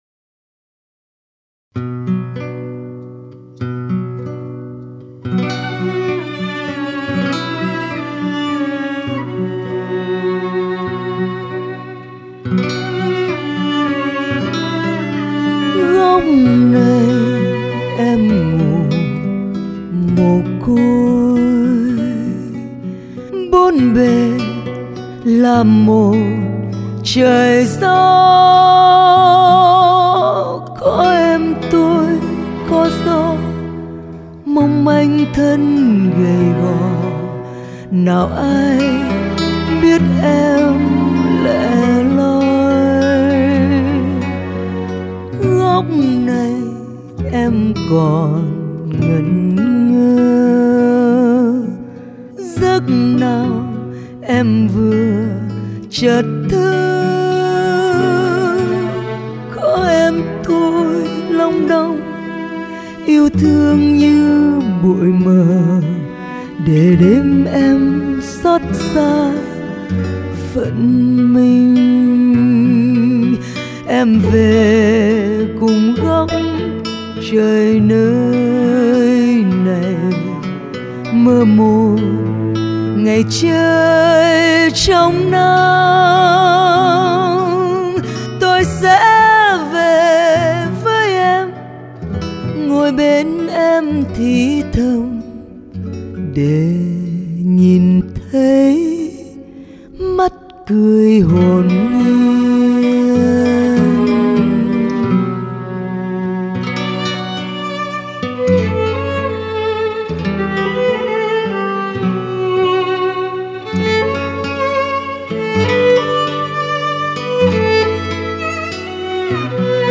an emotional performance